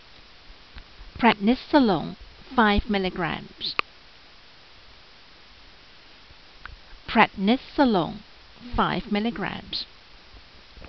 Pronunciation[edit]